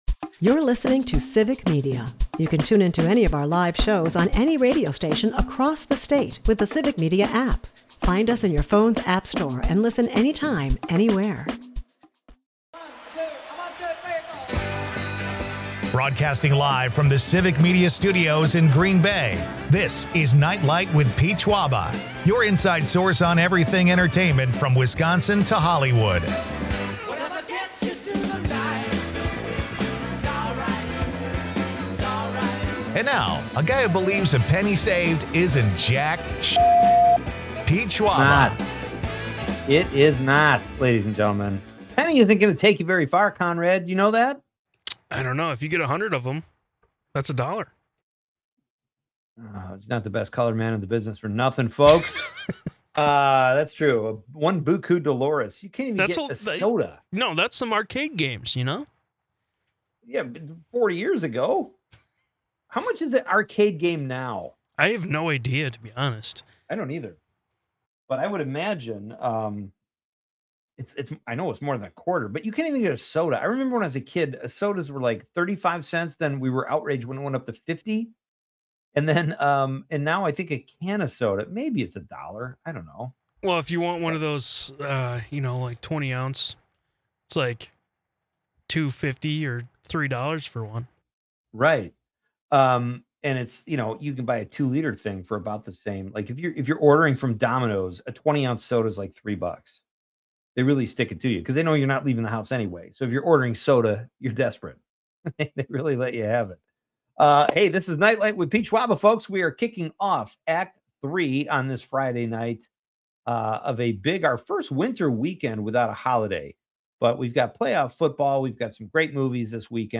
The lively mix of music, sports, and movie chatter sets the weekend tone.